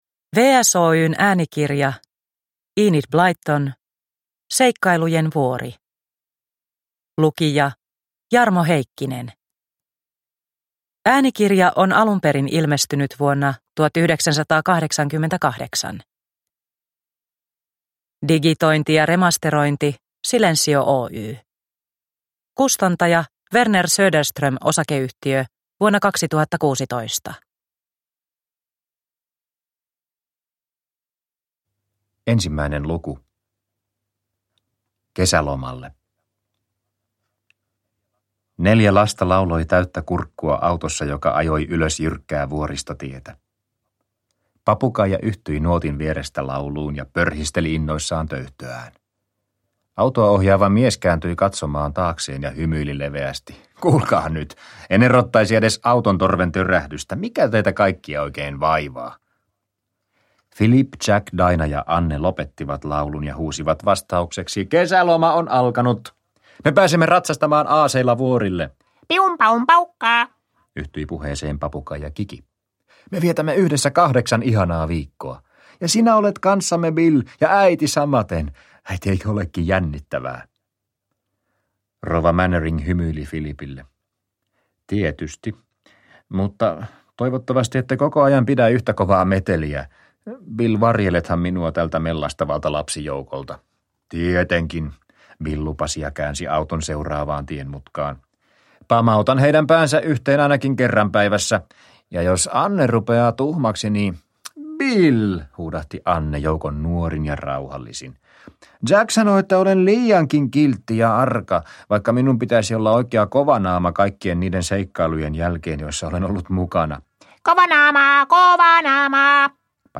Seikkailujen vuori – Ljudbok – Laddas ner